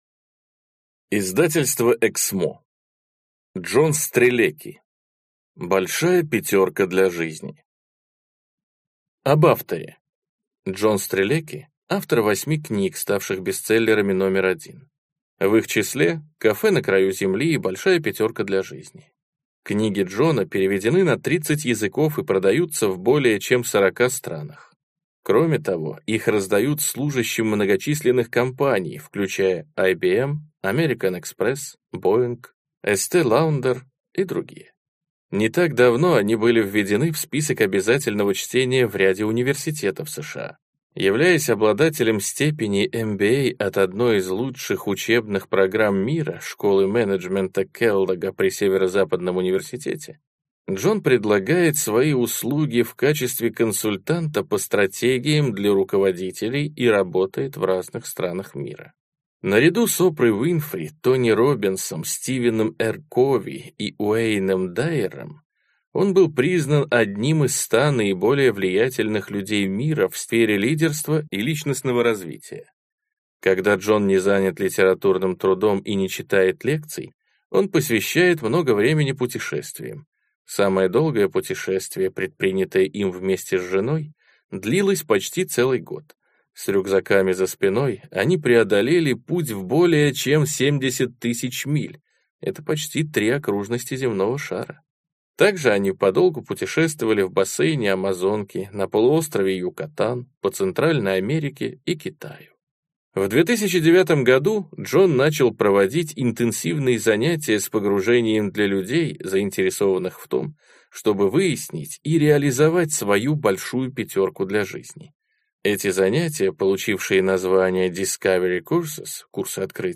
Аудиокнига Большая пятерка для жизни. Как найти и реализовать свое предназначение | Библиотека аудиокниг